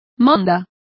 Complete with pronunciation of the translation of trimmings.